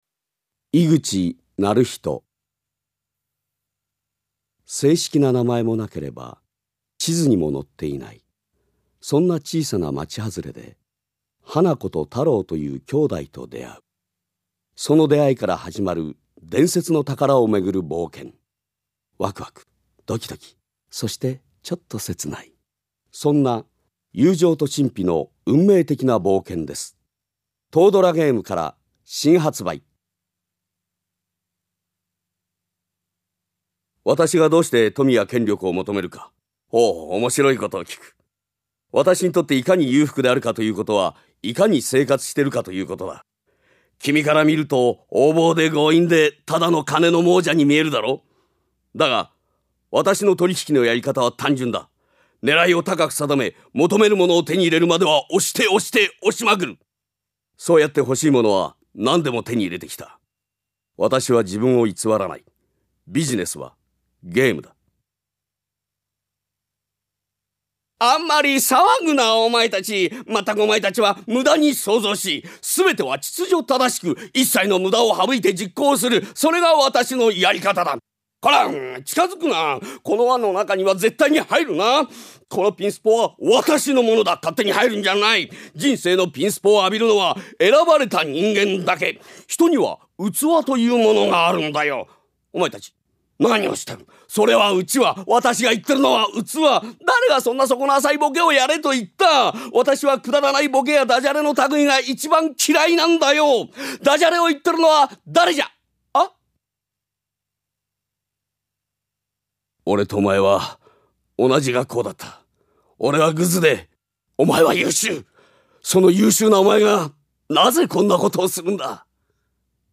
VOICE SAMPLE
ボイスサンプル